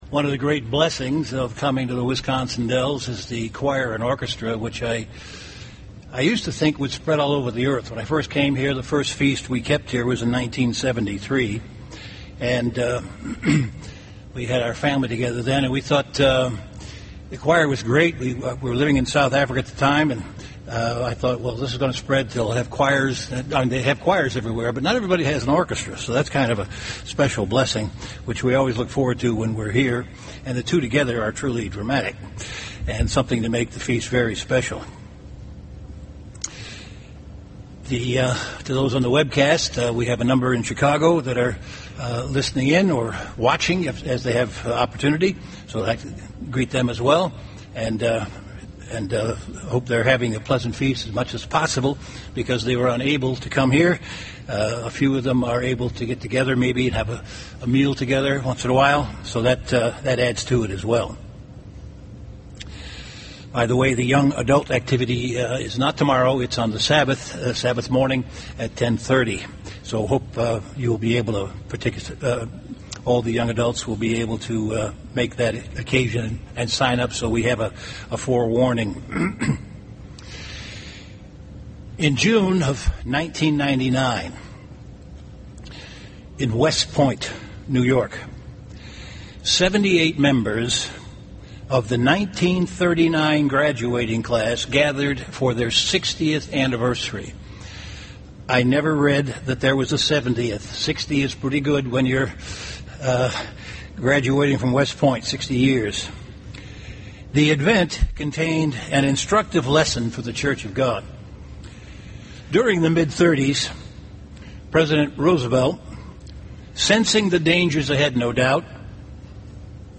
This sermon was given at the Wisconsin Dells, Wisconsin 2011 Feast site.